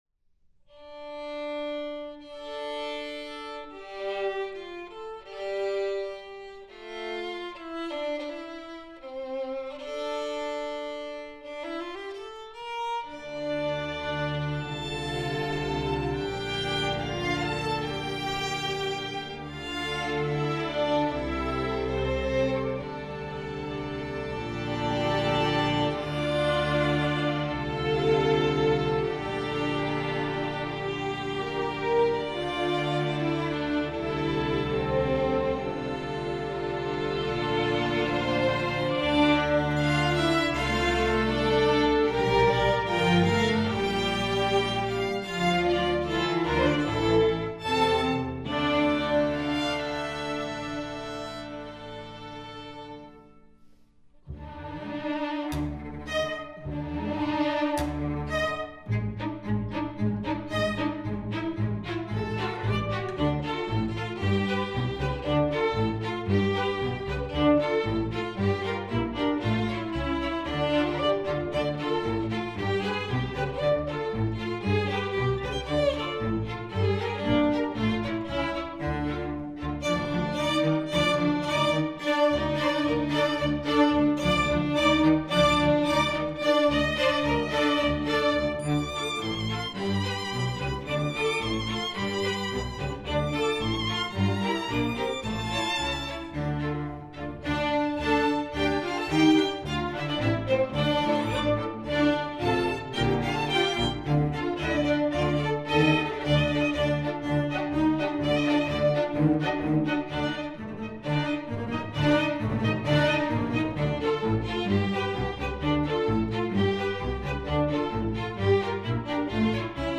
traditional, inspirational, jewish, instructional
Klezmer Music for Strings